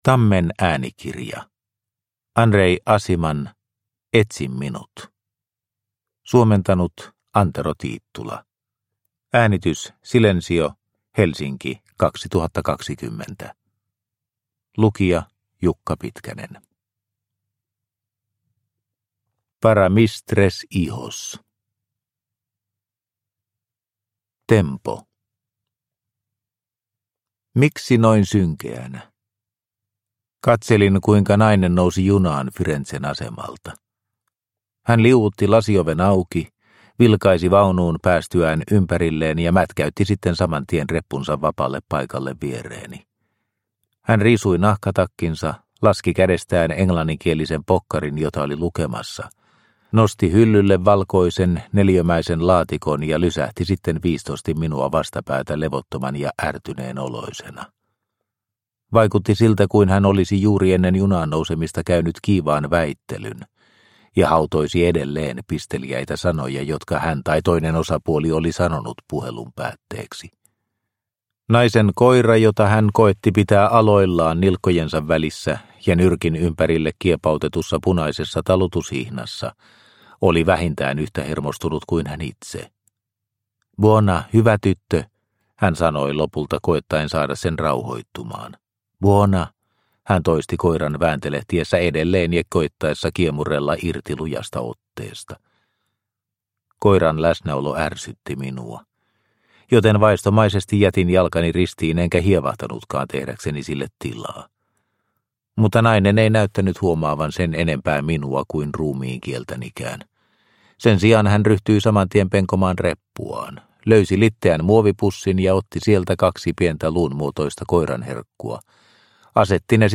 Etsi minut – Ljudbok – Laddas ner